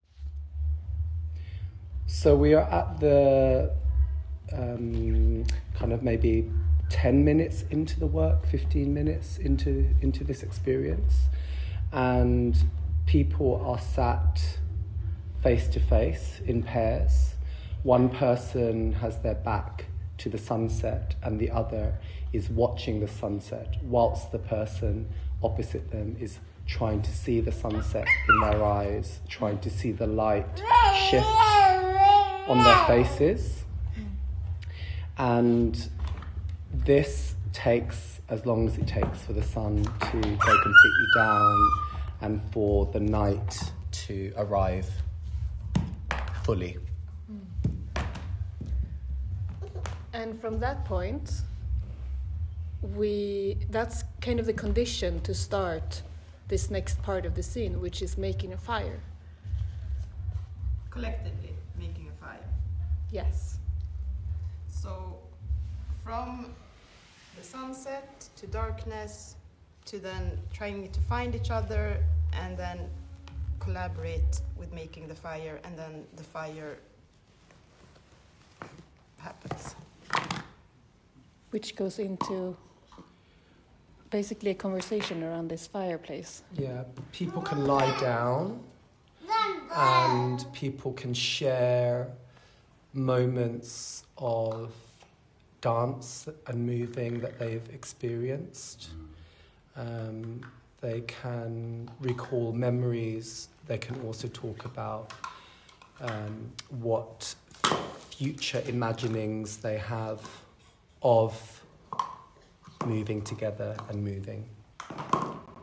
en fireplace
en nature
en conversation